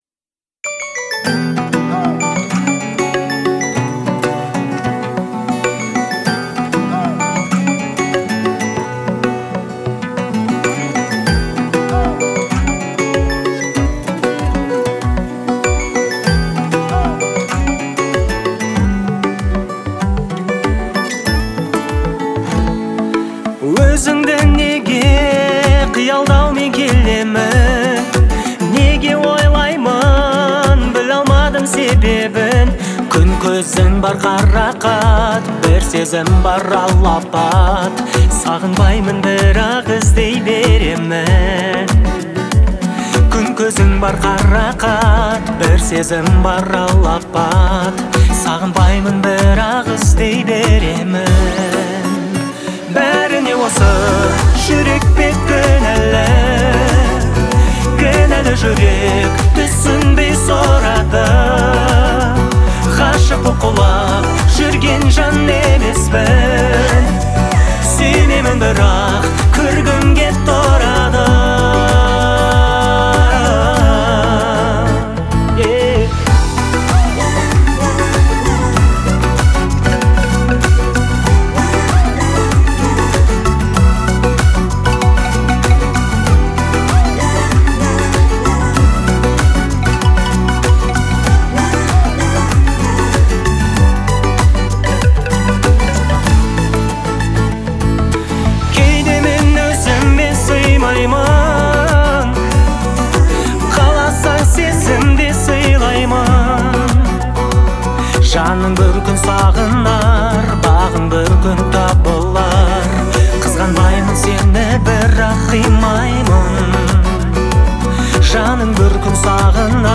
гармоничными мелодиями
выразительным вокалом